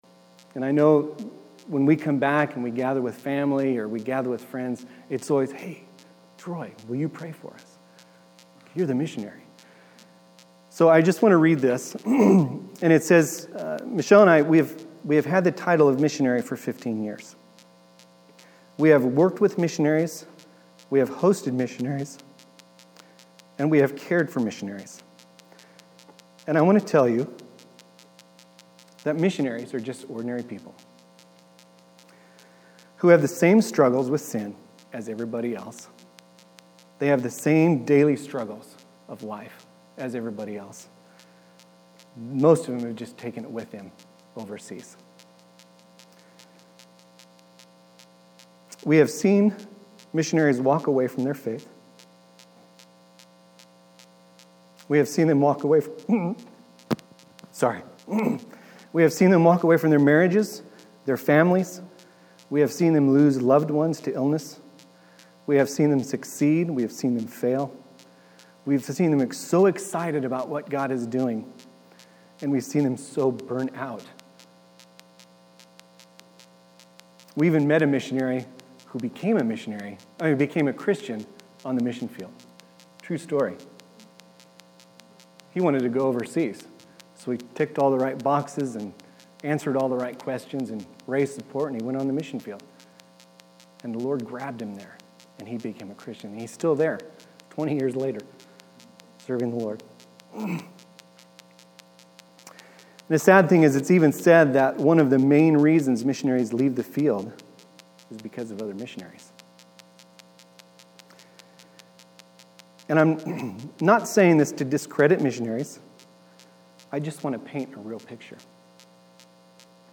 Central Baptist Church Sermons